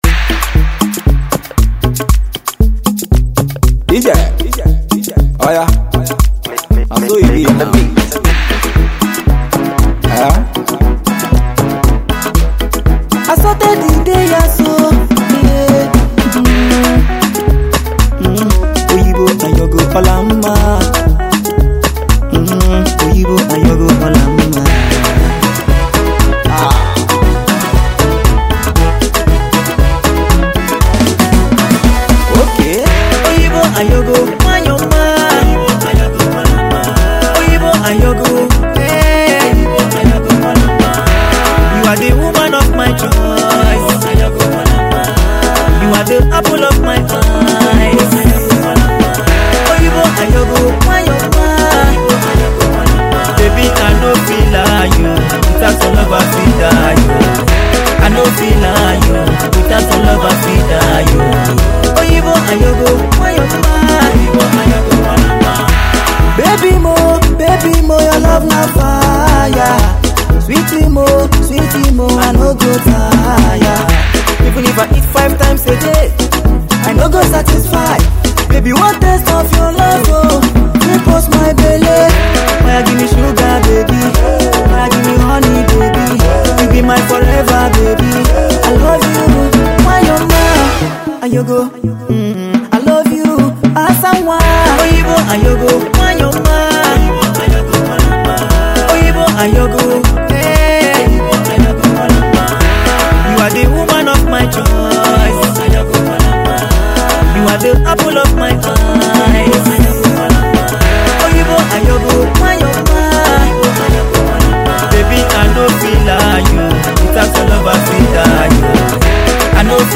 contemporary highlife